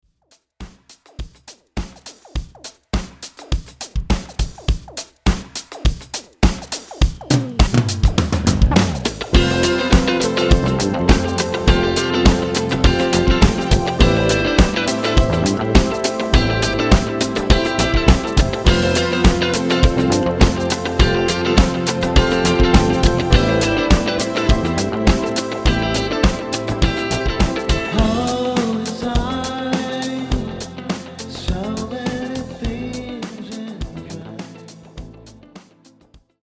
개인적으로는 꽤 뉴웨이브스럽다고 생각했지만
오늘 다시 들어보니 전주가 엄청 비장하네.
보컬 멜로디도 그런 면이 있는데 아마 그래서 까였나?ㅋㅋㅋ
데모 버젼은 악기 더빙도 별로 안하고 완전 스케치 수준이므로